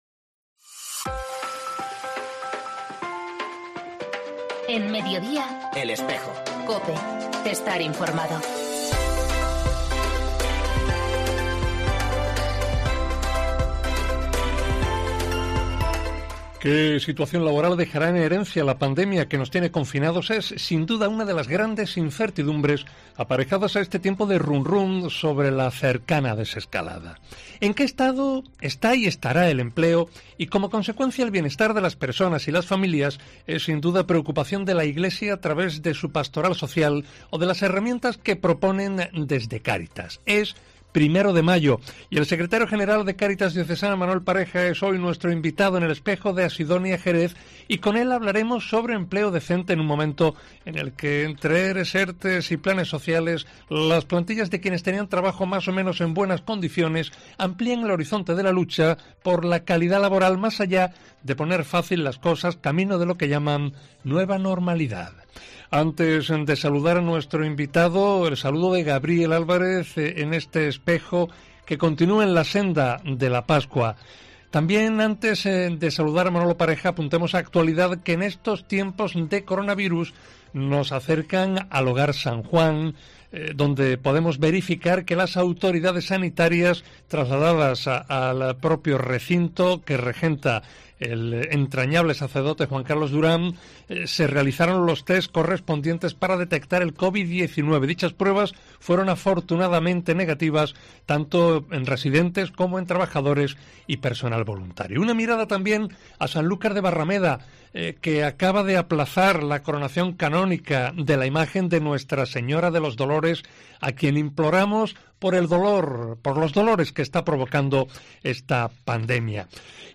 El programa también reproduce el audio de un video que, en apoyo a los sacerdotes en especial por su empeño pastoral enmedio de las limitaciones del confinamiento, han promovido desde la Delegación Diocesana de Apostolado seglar con testimonio de diversos fieles.